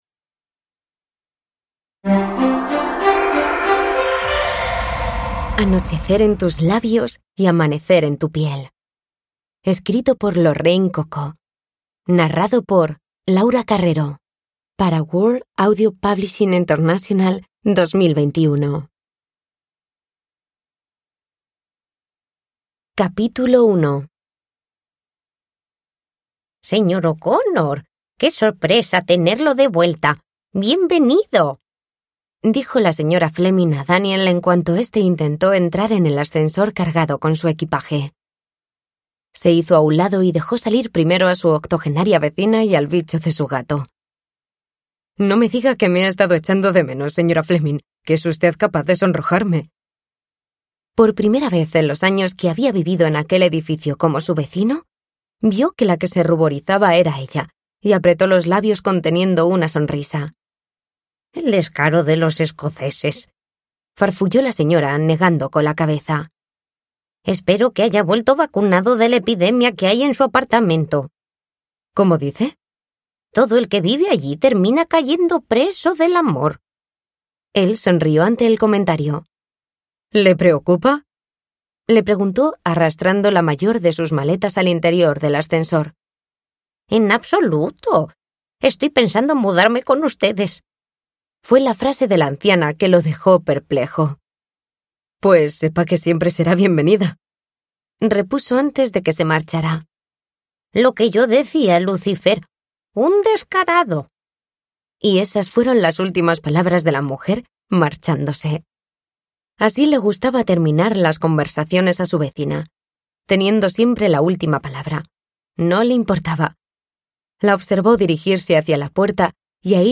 Anochecer en tus labios y amanecer en tu piel (ljudbok) av Lorraine Cocó